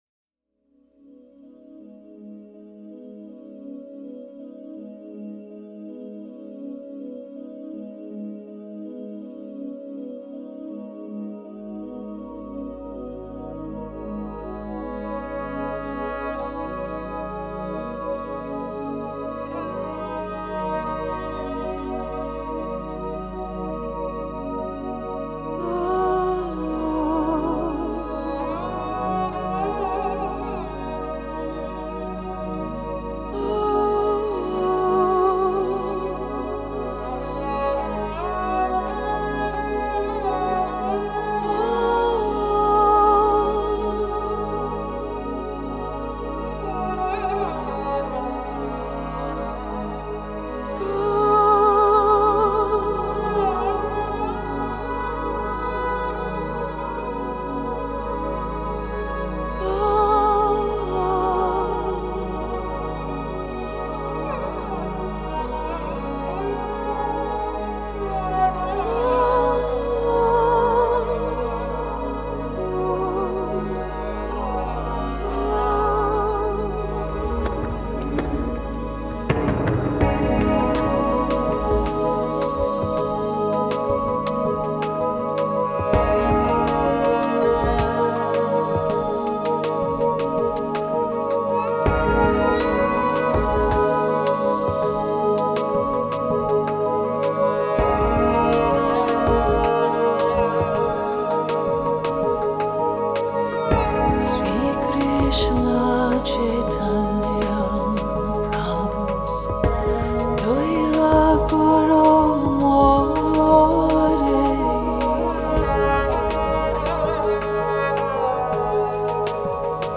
американский нью-эйдж дуэт из Сан-Франциско
индуистскую религиозную музыку в современной обработке